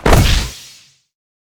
magic_1001_sj.wav